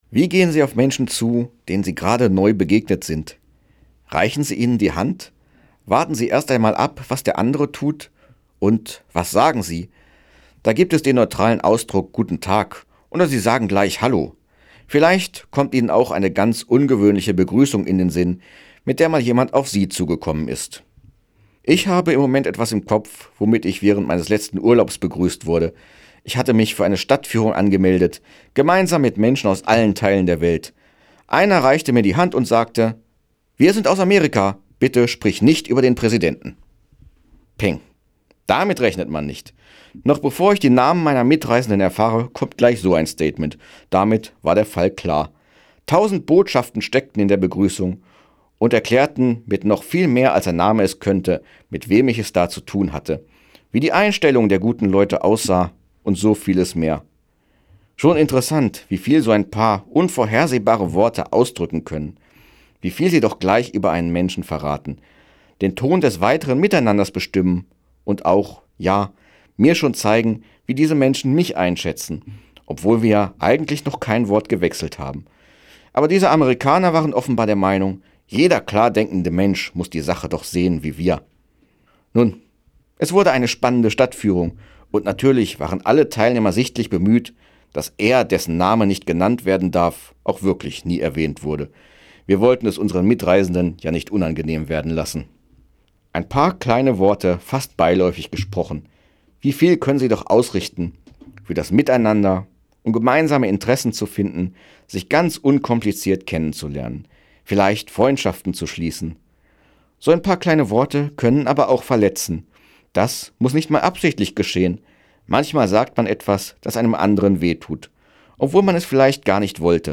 Weserbergland: Radioandacht vom 23. April 2025